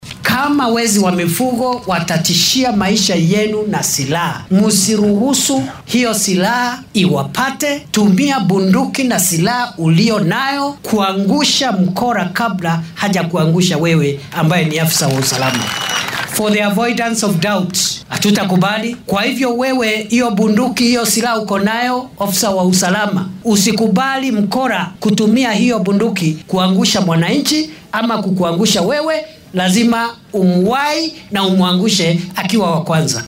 Kindiki ayaa xilli uu ku sugnaa ismaamulka Meru waxaa uu ciidamada ammaanka amar ku siiyay in haddii ay naftooda halis ku jirto ay burcadda xoolaha dhacda iskaga difaacaan hubka ay dowladdu siisay oo ay toogtaan. Wasiirka ayaa ku celceliyay in aan marnaba la aqbali karin in shakhsiyaad shacabka dhibaateynaya ay khatar geliyaan nolosha ciidamada ammaanka.